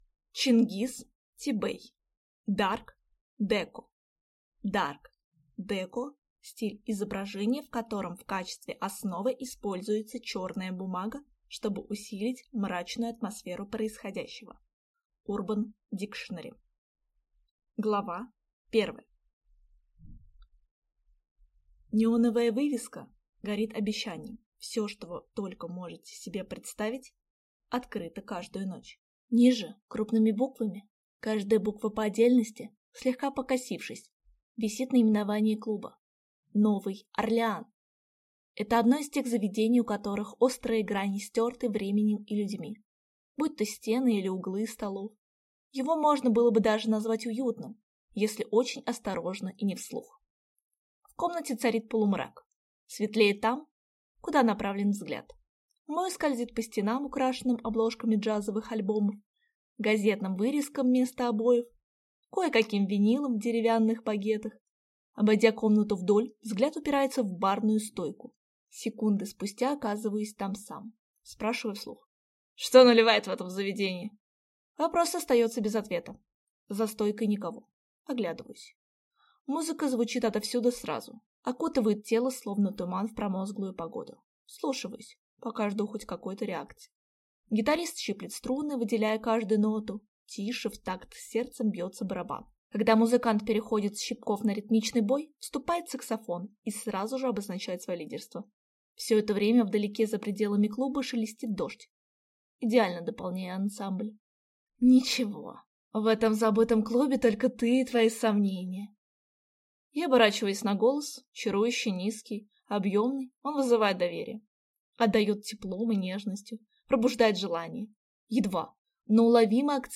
Аудиокнига Dark Déco | Библиотека аудиокниг